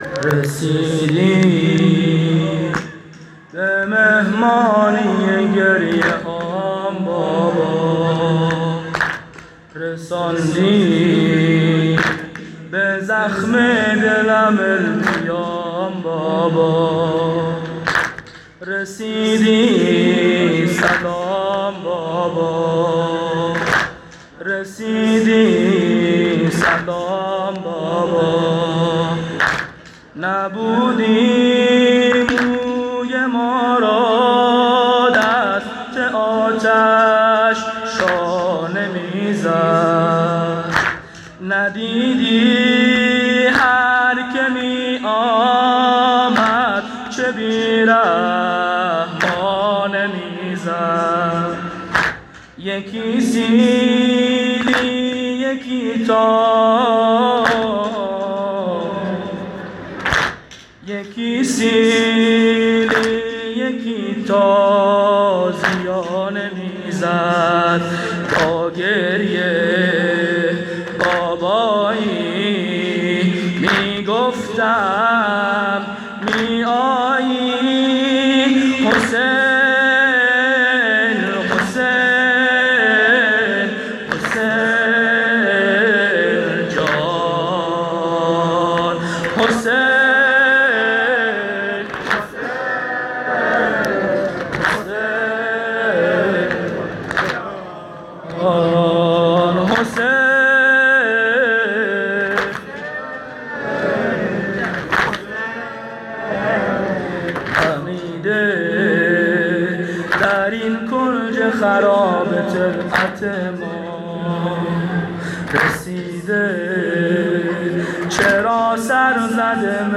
شب سوم محرم98 هیئت میثاق الحسین (ع) سیستان